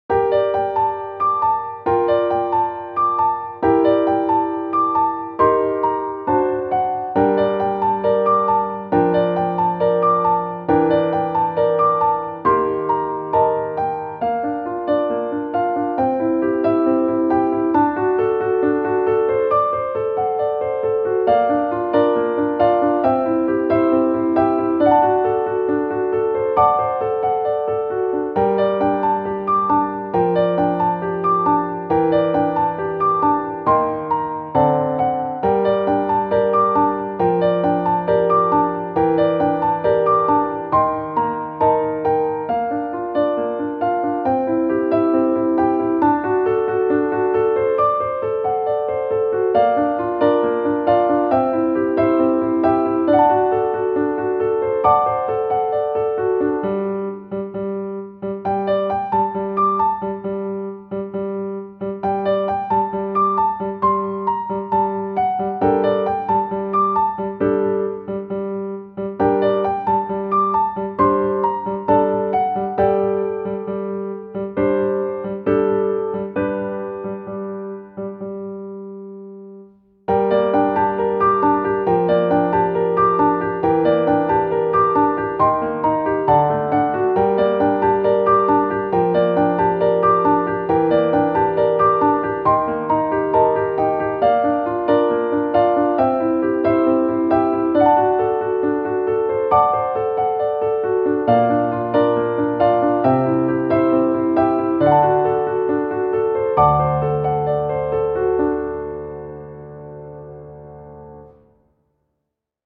ogg(R) - 不安 哀愁 情熱的